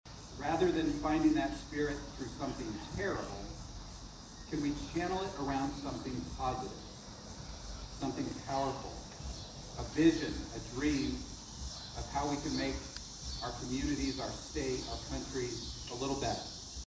City Park was host to the 9/11 Day of Remembrance on Sunday where several dignitaries were on hand for the event.
This included five flags being laid by Kansas Lieutenant Governor David Toland who gave a few remarks at the ceremony.
0910-2-Lt.-Gov-David-Toland.mp3